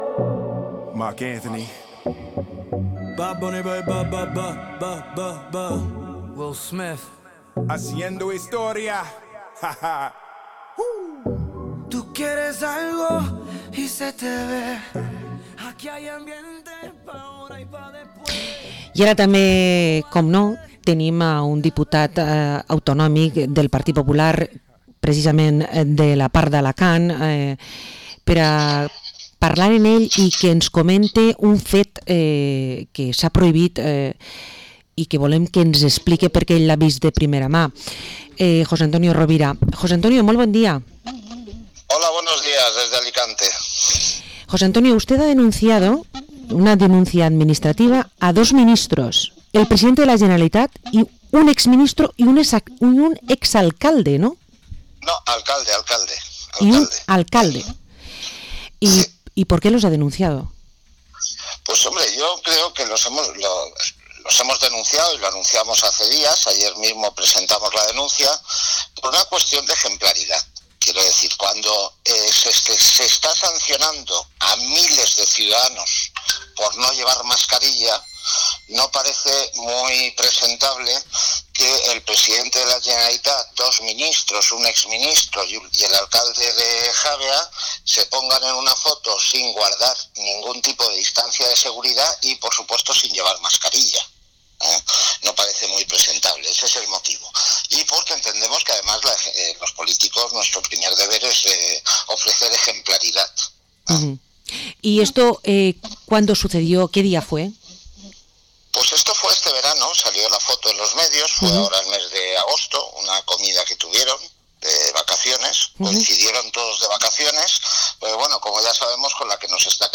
Entrevista al diputado autonómico del PP, José Antonio Rovira